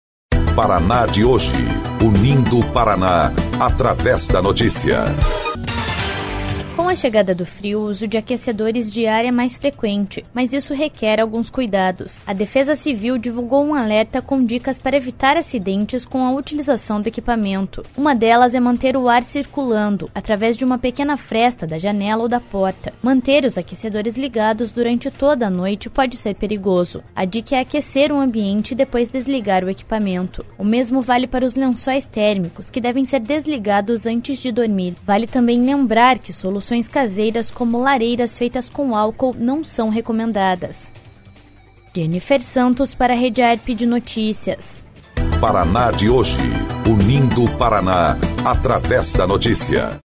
12.07 – BOLETIM – Uso de aquecedores exige cuidados para evitar acidentes